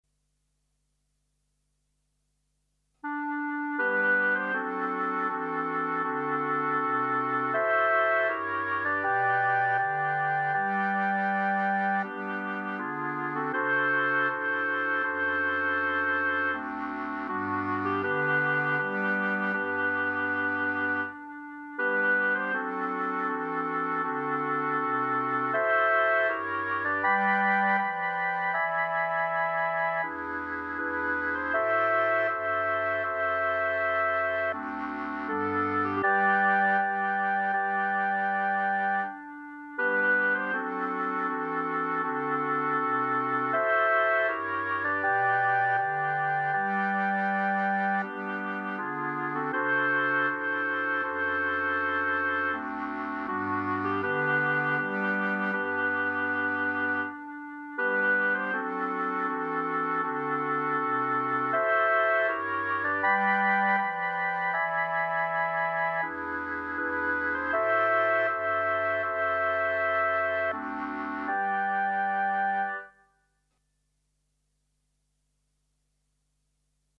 Instrumentation:4 Clarinet , Bass Cl.